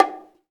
27 CONGA HI.wav